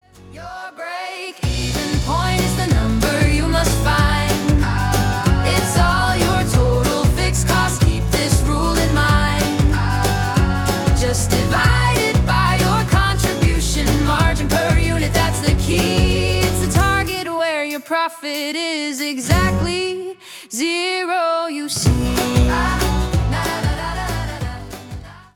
Features two incredibly catchy tracks